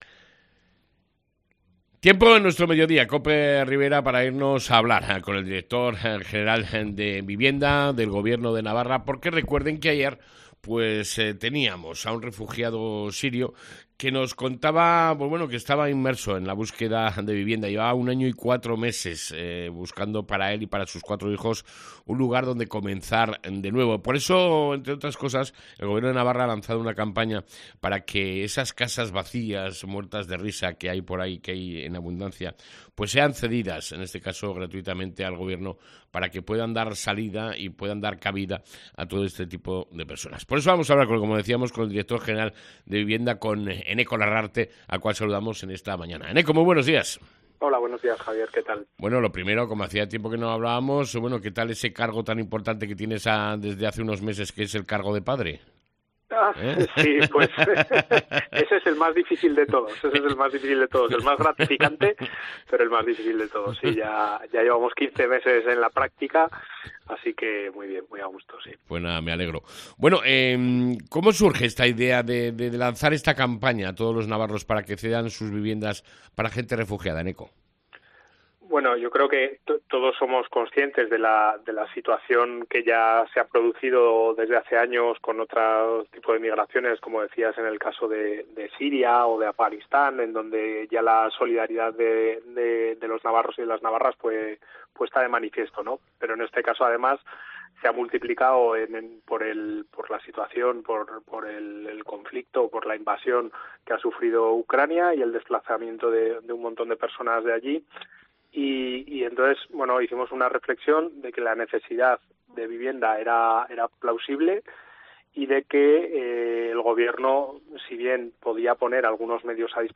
ENTREVISTA CON ENEKO LARRARTE (DIRECTOR GENERAL DE VIVIENDA EN NAVARRA)